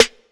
Waka SNARE ROLL PATTERN (57).wav